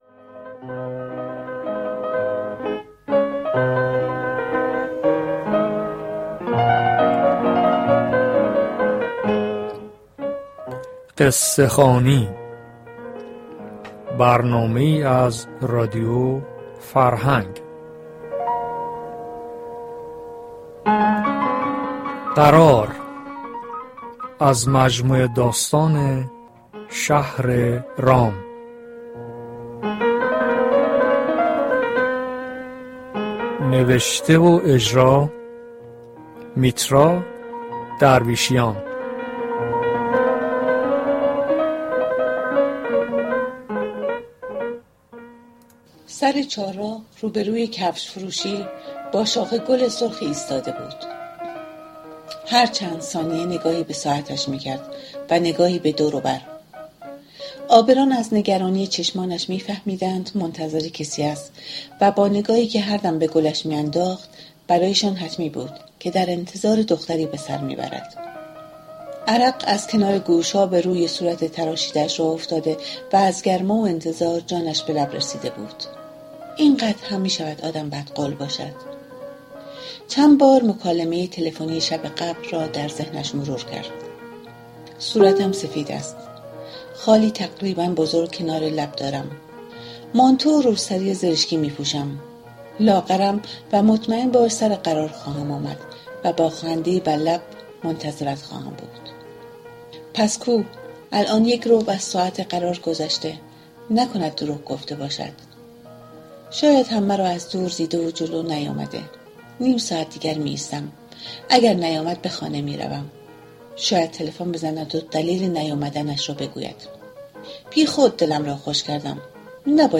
داستان خوانى